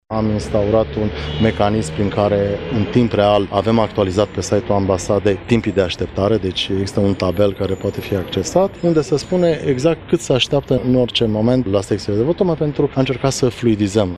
Se votează fără probleme, fără timpi de aşteptare. Ambasadorul României la Chişinău Daniel Ioniţă: